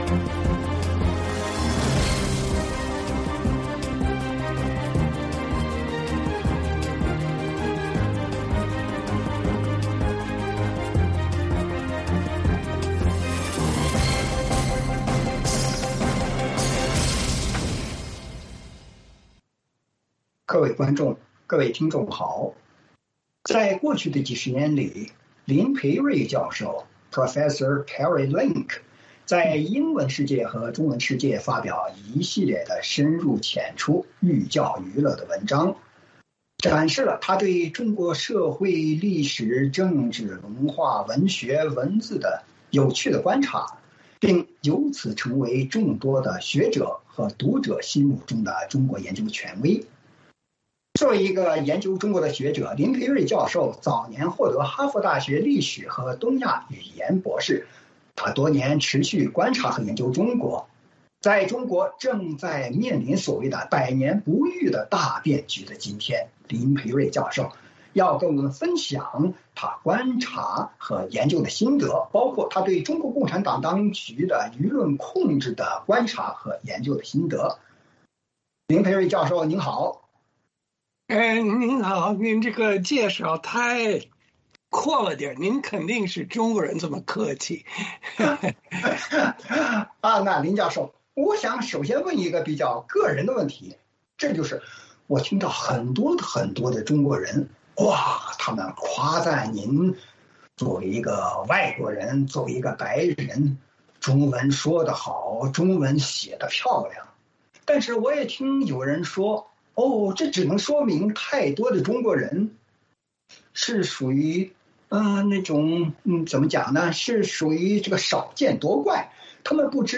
时事大家谈：专访汉学家林培瑞：如何看当今中国语言文化、官民价值观对峙？